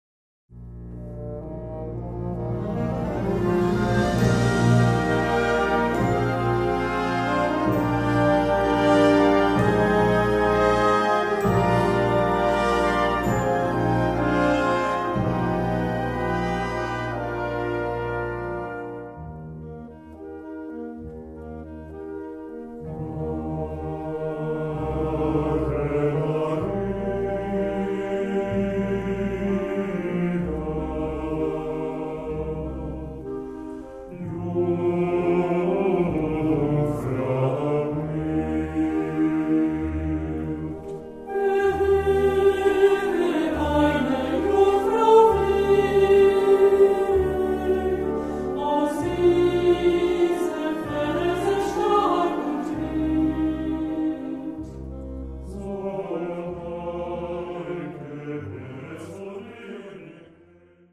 Gattung: SATB
A4 Besetzung: Blasorchester Zu hören auf